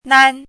“囡”读音
nān
囡字注音：ㄋㄢ
国际音标：nĄn˥